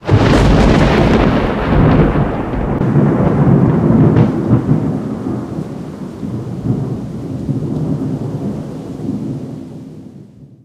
Звук сильный раскат грозы.